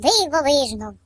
71f94af9d1a4 mono version for ukranian voices